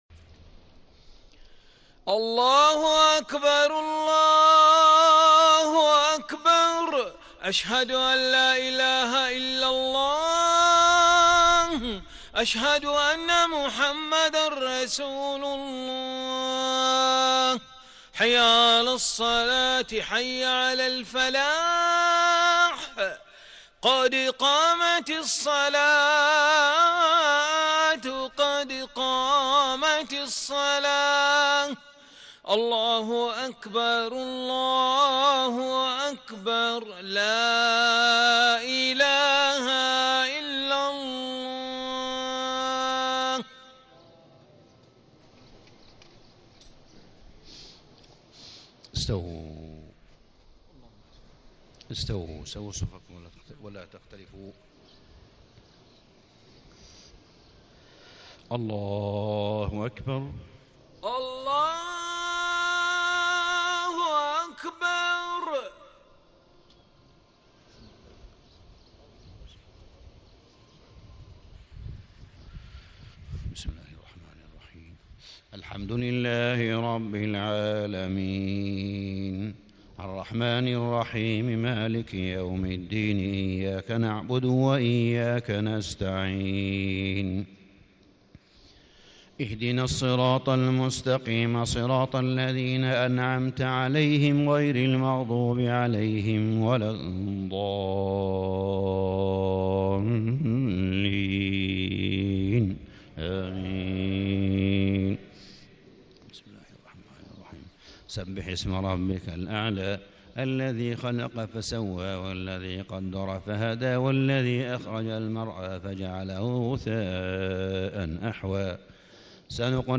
صلاة الجمعة 1 - 5 - 1436هـ سورتي الأعلى و الغاشية > 1436 🕋 > الفروض - تلاوات الحرمين